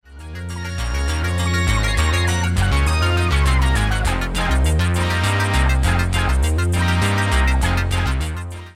L'ARP 2600 est un synthétiseur analogique semi-modulaire, c'est à dire que des modules sont pré-cablés en hardware, le principal intérêt était d'offrir un synthé analogique puissant et compact pour la performance sur scène.
Demo excerpt (Vintage Synths)
arp_2600_vintagesynths_excerpt.mp3